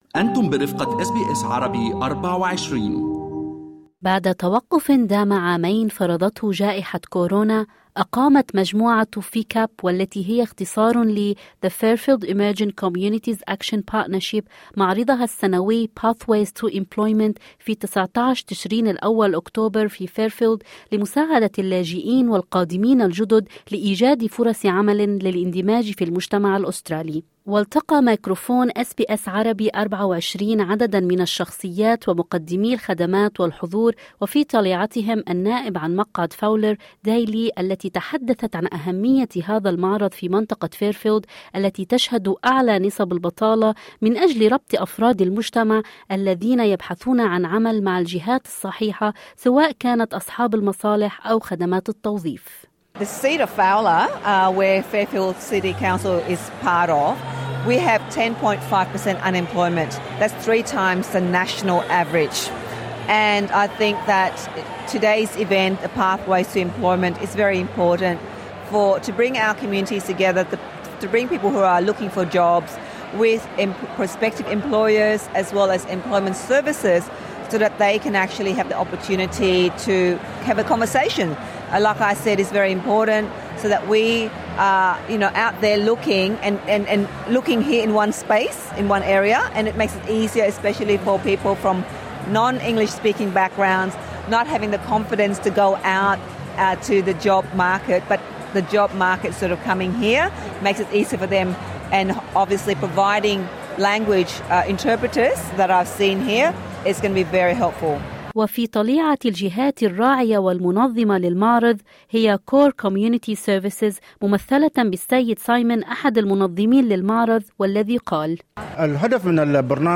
وقد جال ميكروفون أس بي أس عربي 24 على عدد من الشخصيات ومقدمي الخدمات والحضور، وتحدث إلى عضو البرلمان عن مقعد فاولر داي لي التي أشارت إلى نسبة البطالة العالية في منطقة جنوب غرب سيدني، وقالت أن هذا المعرض هو الفرصة المناسبة لتمكين المهاجرين واللاجئين لبدء الحوار مع الجهات التي تمكنهم من إيجاد فرص في حال كانوا لا يعرفون من أين يبدؤون.